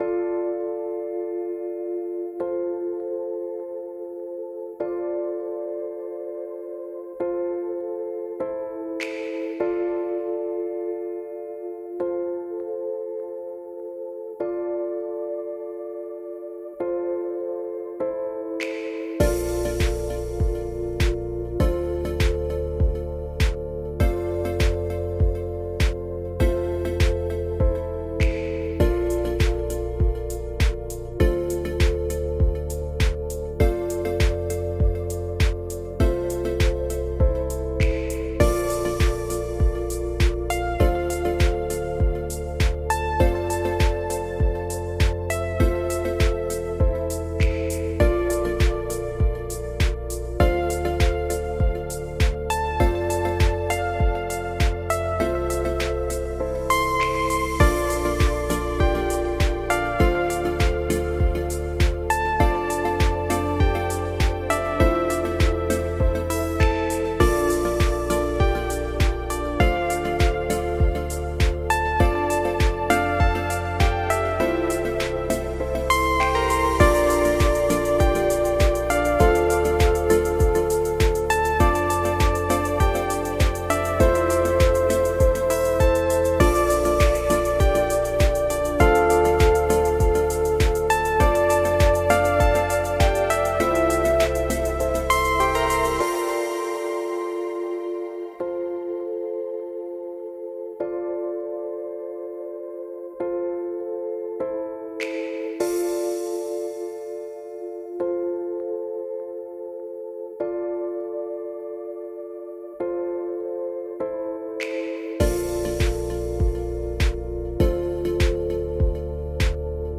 Лучшая музыка для монтажа видео